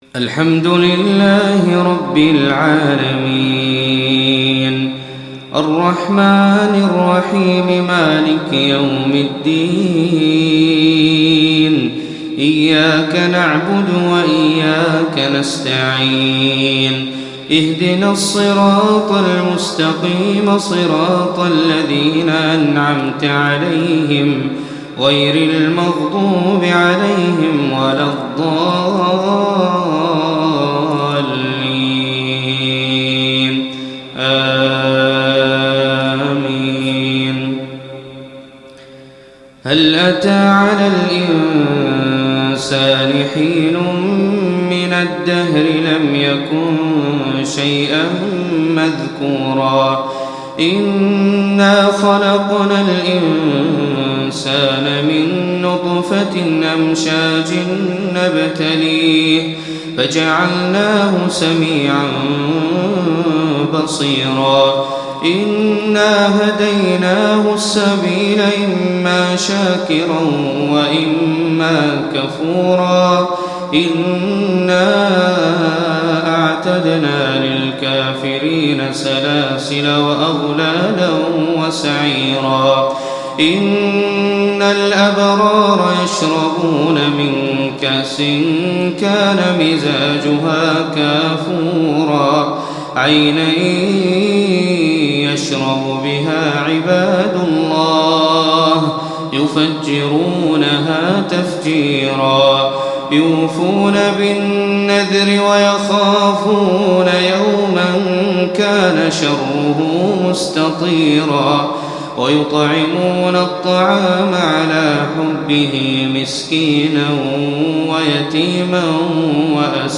Surat Al Insan Reciter Hazza Alblushi
Quran recitations